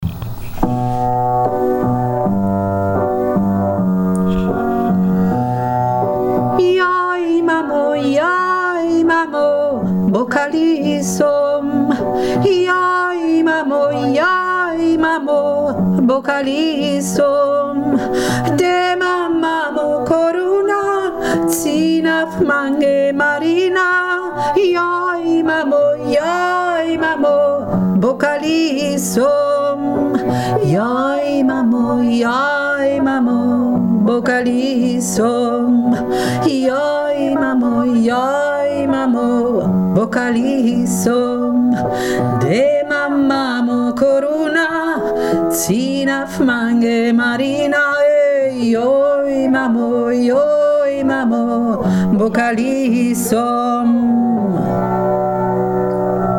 Joy Mamo - Klagelied der Roma
zweite Stimme
joi-mamo-zweite-stimme.mp3